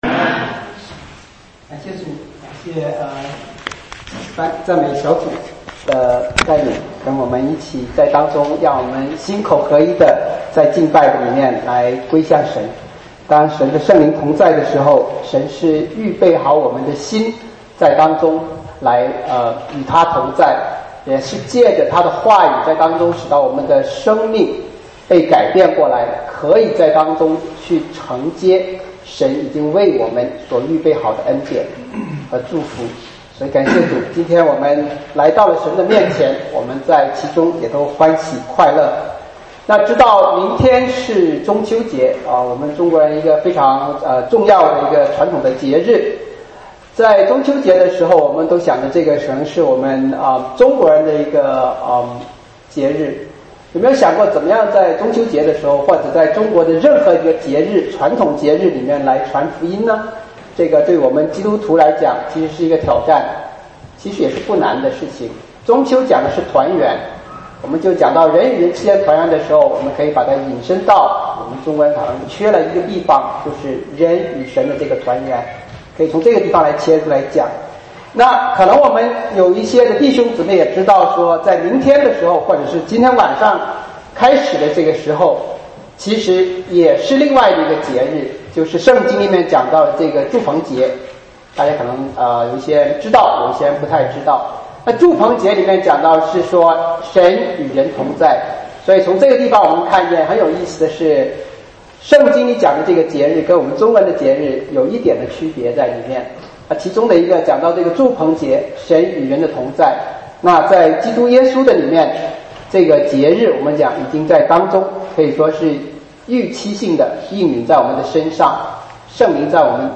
2018主日讲道录音